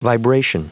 Prononciation du mot vibration en anglais (fichier audio)
Prononciation du mot : vibration